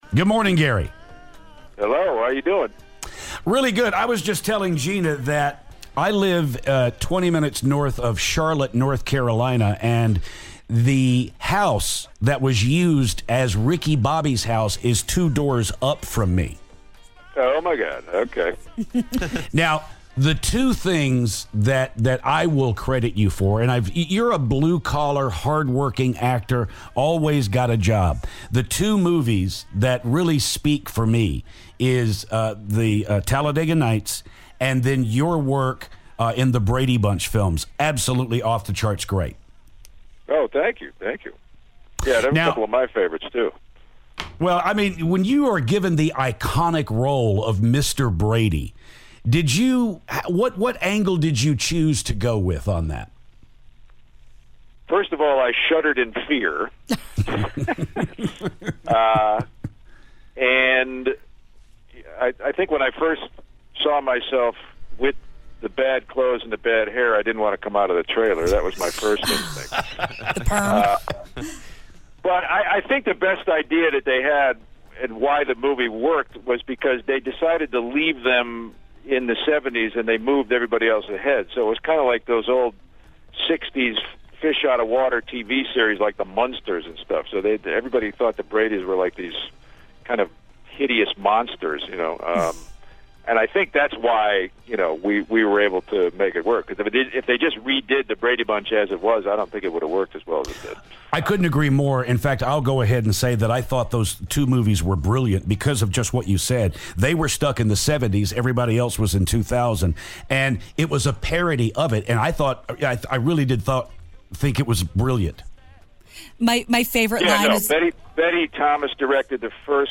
Gary Cole Phoner
Actor Gary Cole calls to talk about his new movie "The Bronze."